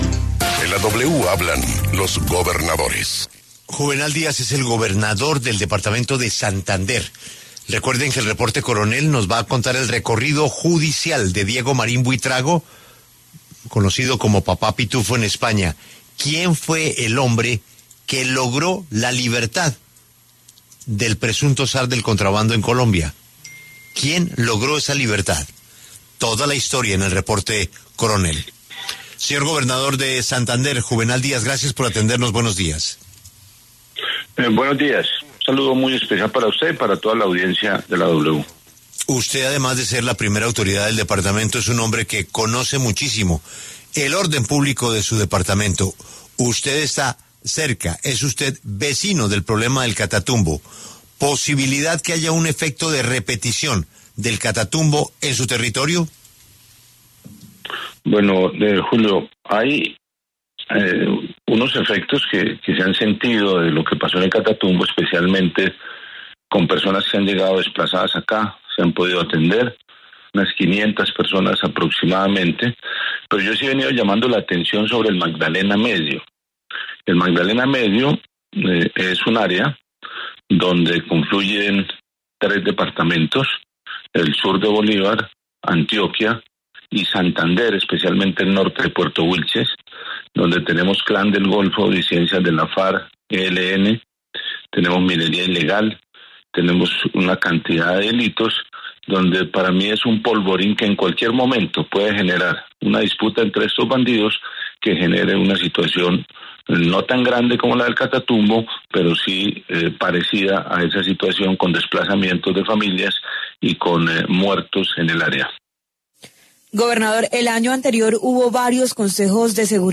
El gobernador de Santander, Juvenal Díaz Mateus, pasó por los micrófonos de La W, con Julio Sánchez Cristo, para hablar sobre la situación de orden público que se viene presentando en el Magdalena Medio y la alerta que se hace ante la posibilidad de que se pueda generar una confrontación entre grupos al margen de la ley como se presentó en El Catatumbo.